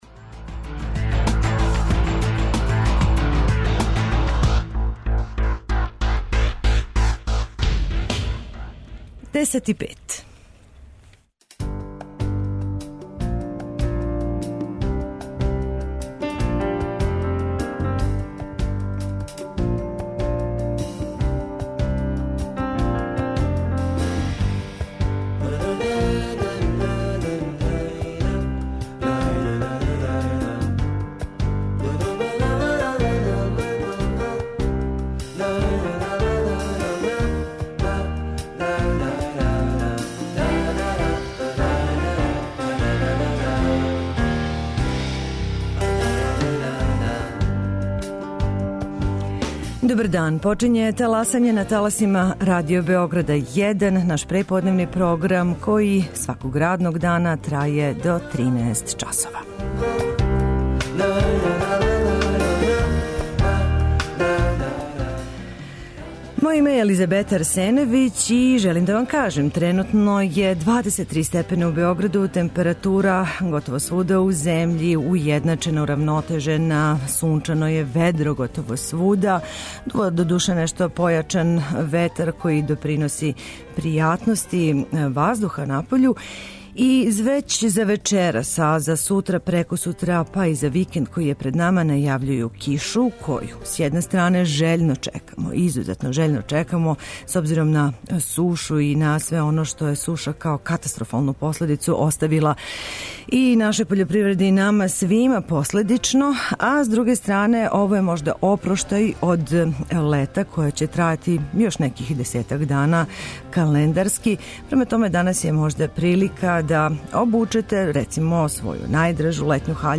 О коликој и колико значајној инвестицији у домаћи туризам је реч чућемо од државног секретара у Министарству финансија и привреде Горана Петковића.